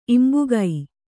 ♪ imbugai